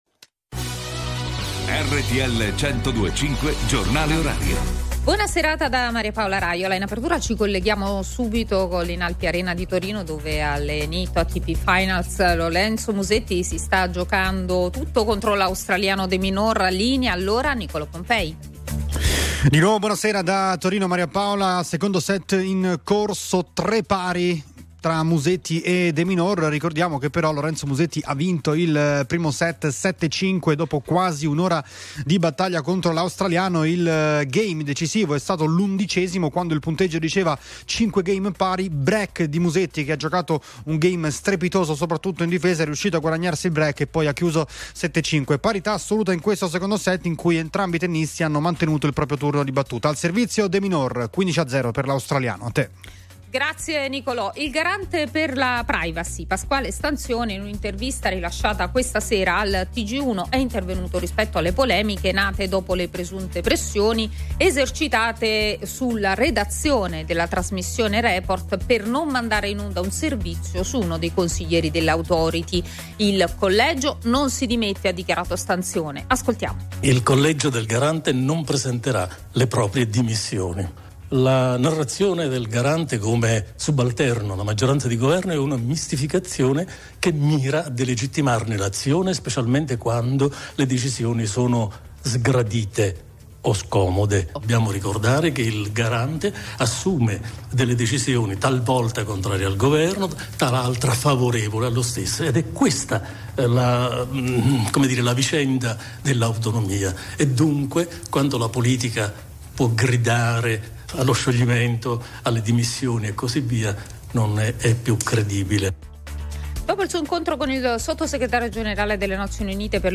Il giornale orario di RTL 102.5 a cura della redazione giornalistica